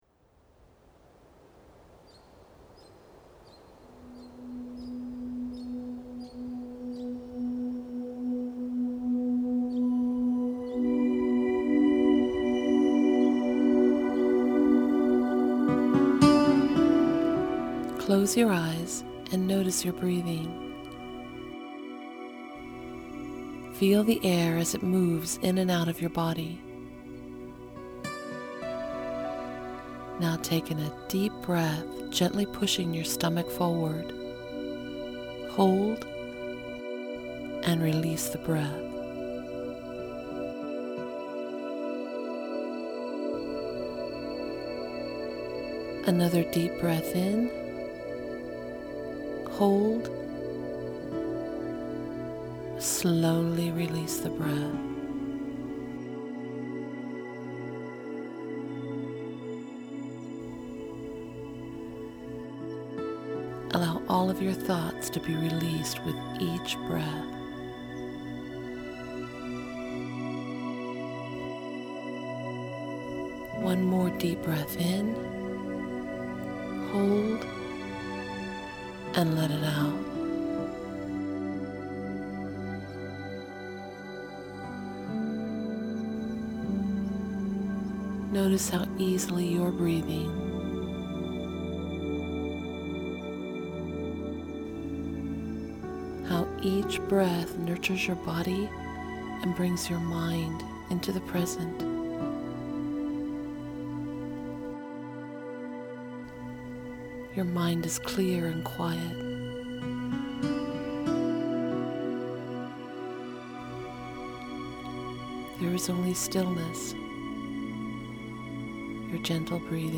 This Quiet Moment guided meditation is less than 3 minutes long and can help you clear your head and shift your energy.
Whether you have a little or a lot of experience with meditation, it can be so helpful to have a gentle voice guiding your mind to release and relax.